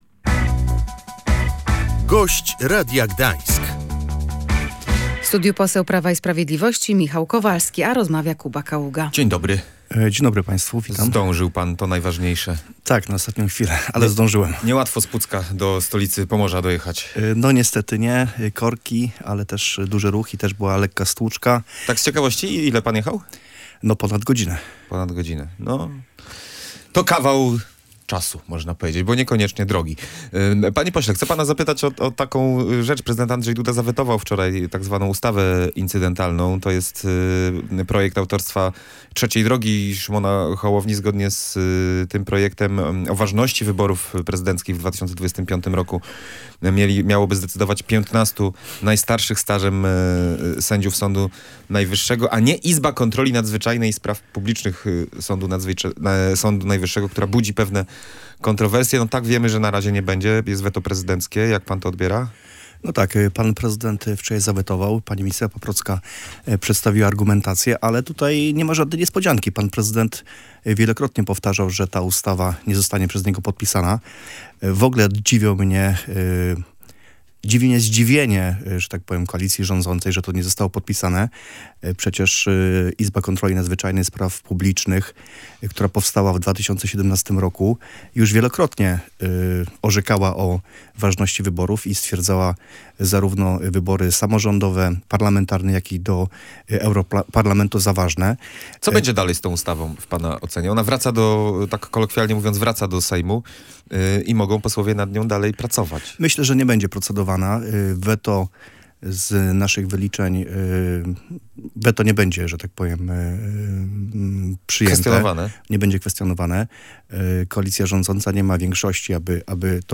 Kwestionowanie wyników wyborów przez niektórych posłów koalicji rządzącej to brak logiki – mówił w Radiu Gdańsk Michał Kowalski, poseł Prawa i Sprawiedliwości.